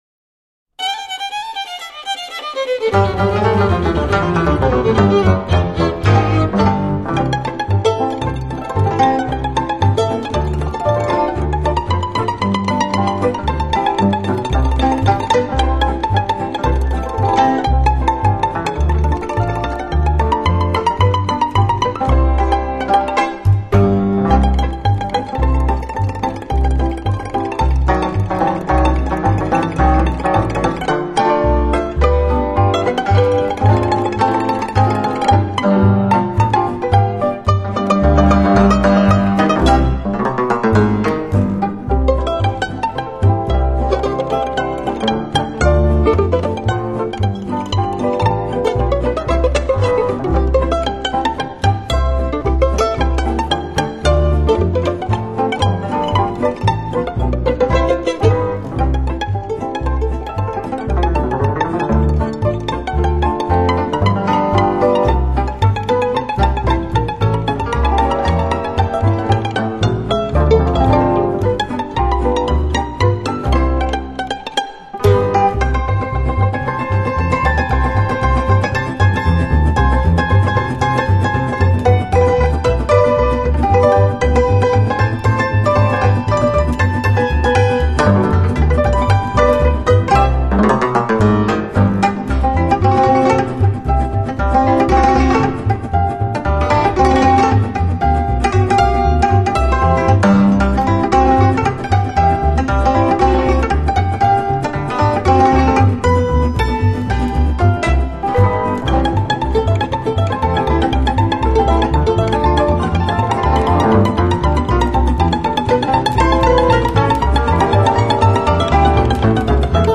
你也将随着一些耳熟能详的旋律，嗅到奔放动人的吉普赛气息。